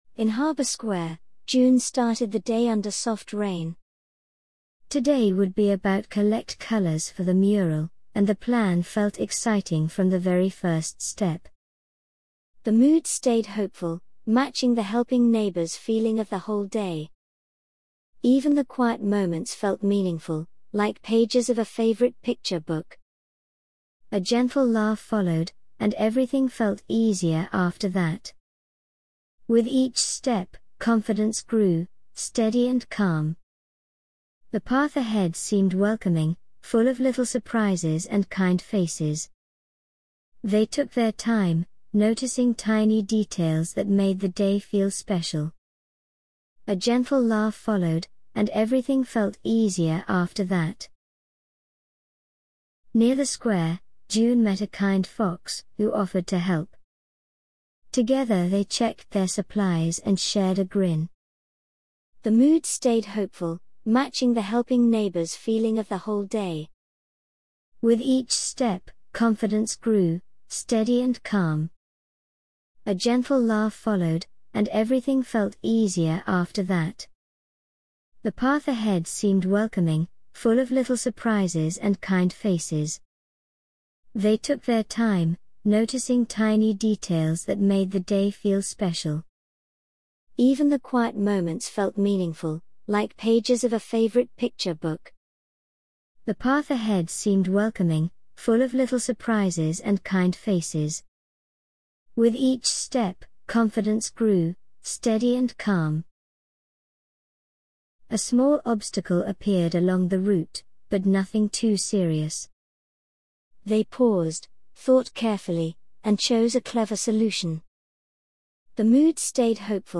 narration.mp3